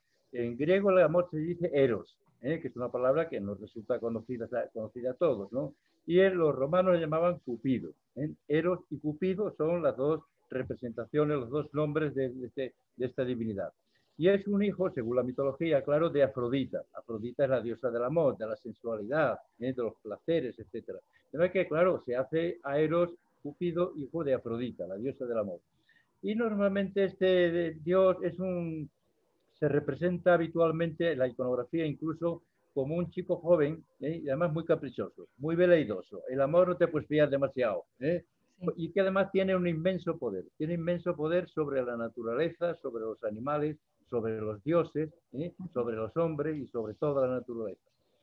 Las charlas online han contado con la participación de una voz experta